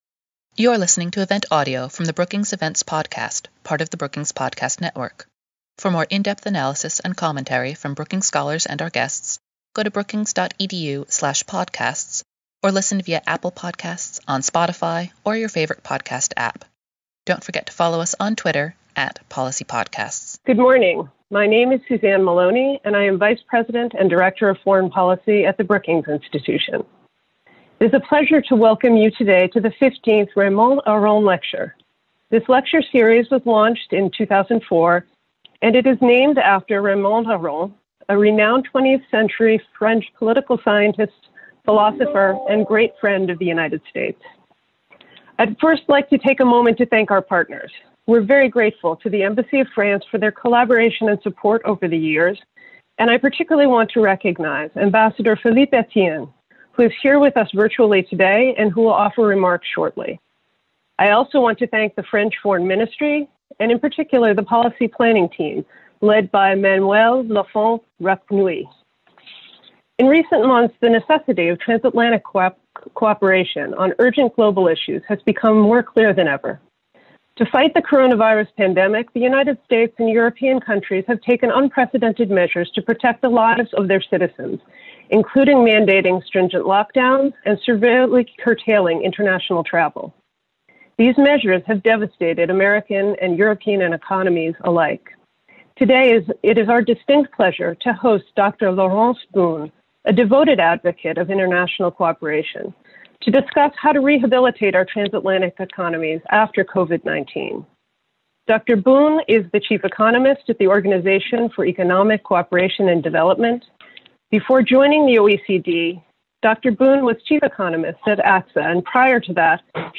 Following her address, Harvard University professor Jason Furman, who served as the 28th chairman of the Council of Economic Advisers from August 2013 to January 2017, responded to her remarks.
After the program, panelists answered questions from the audience.